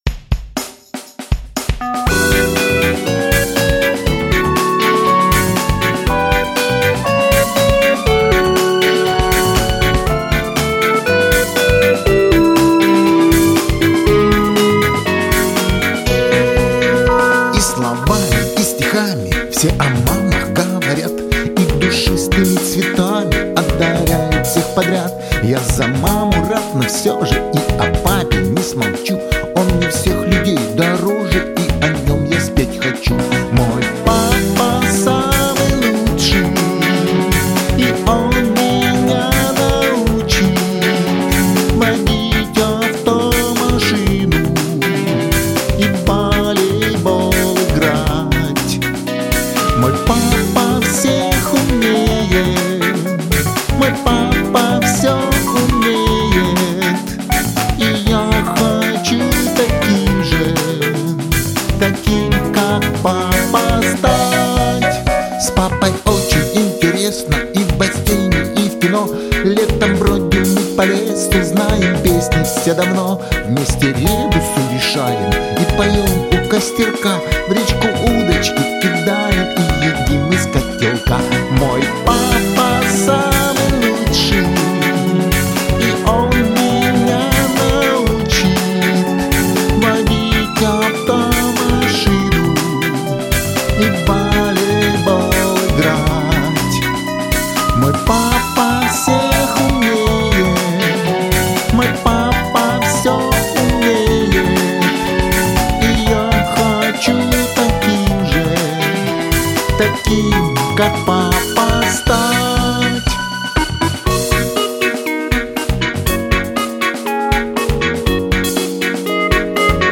Главная / Песни для детей / Песни про папу